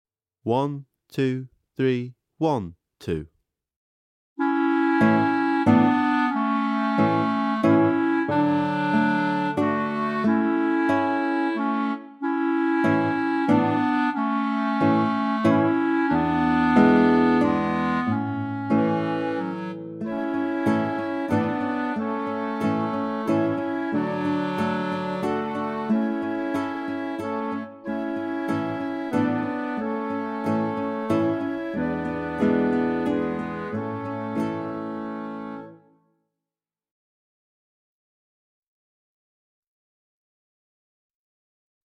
VS Vilanova (backing track)